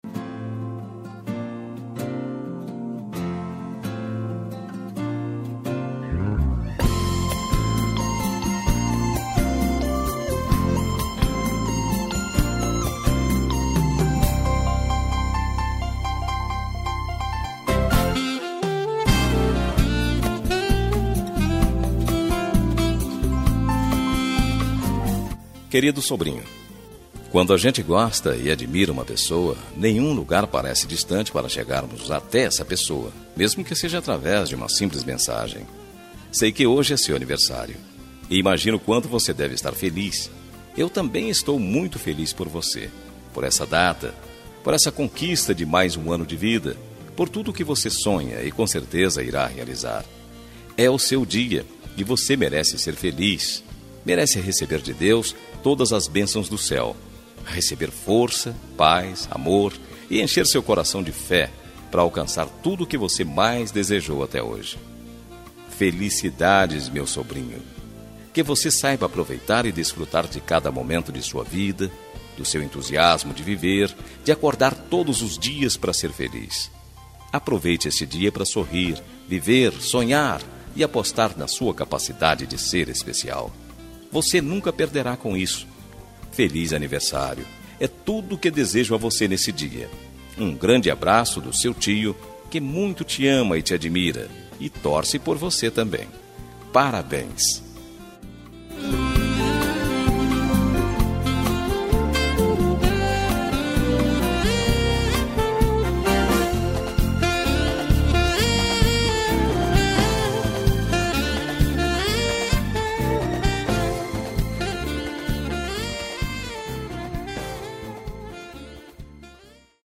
Aniversário de Sobrinho – Voz Masculina – Cód: 2680 – Distante
2680-sobrinho-masc-distante.m4a